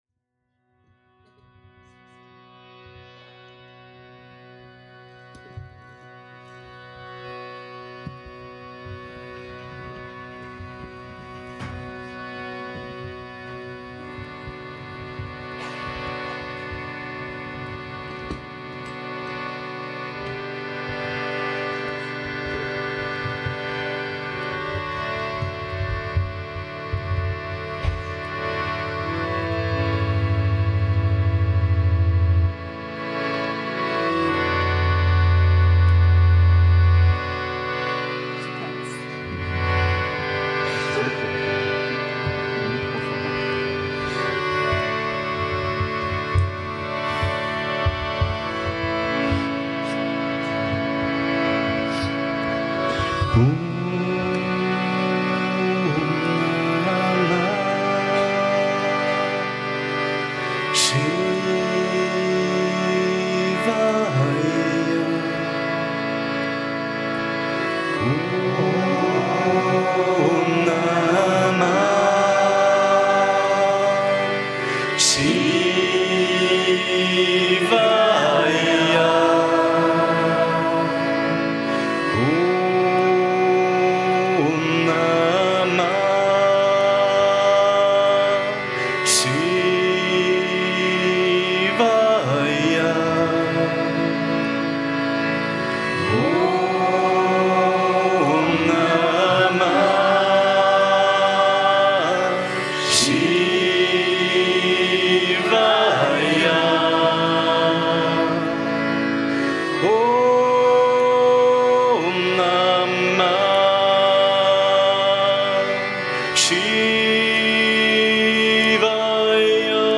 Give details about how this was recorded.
live Kirtan Evening Session